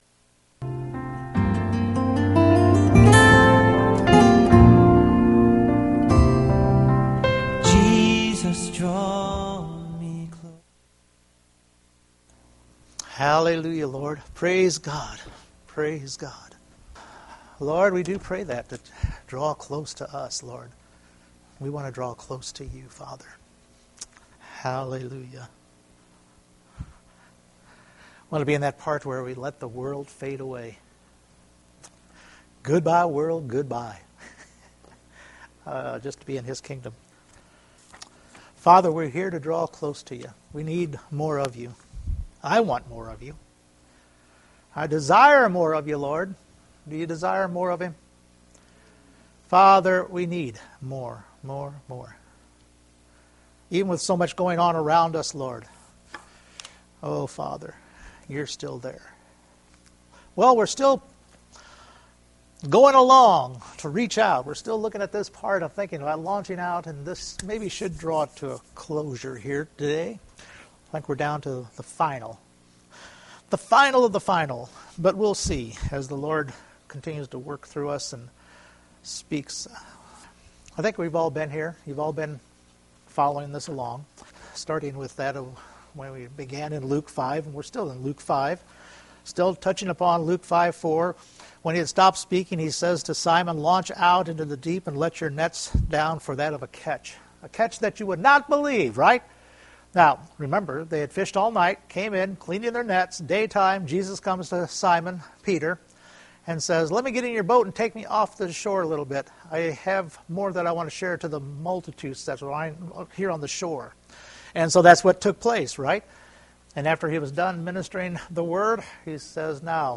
Luke 5:4 Service Type: Sunday Morning Continue working through Luke 5.